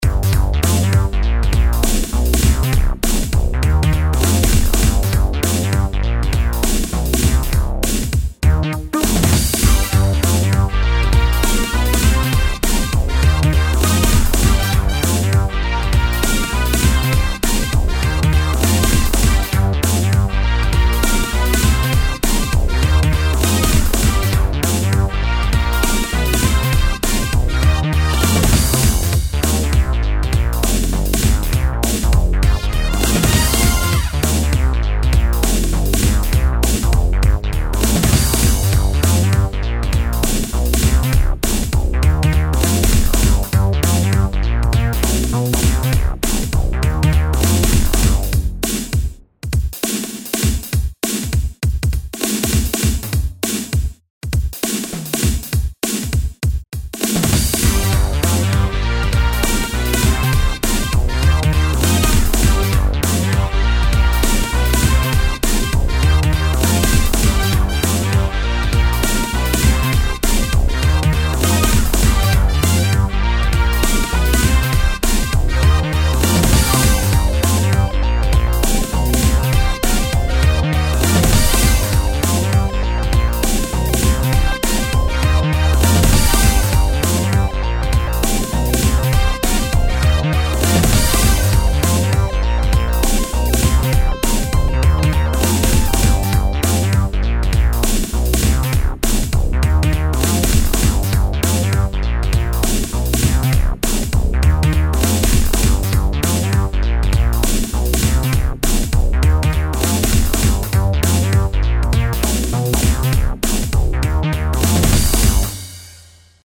Genre: Game